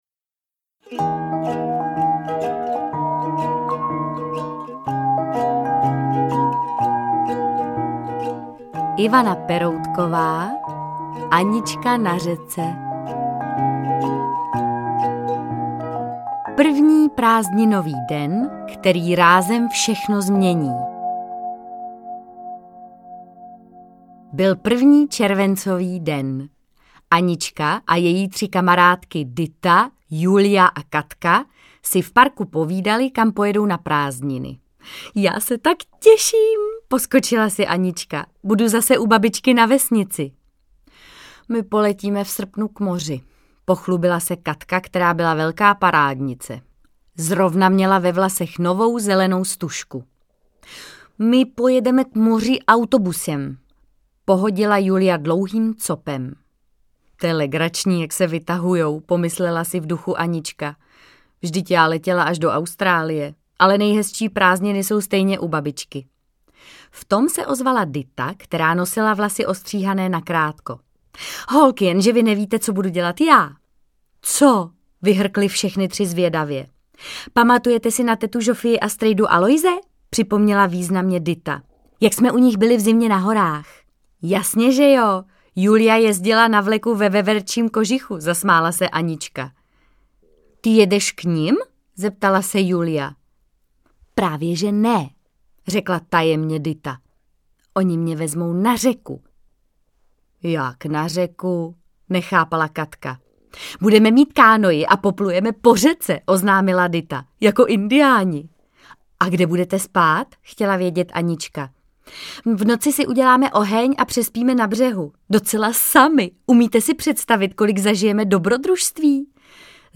Interpret:  Martha Issová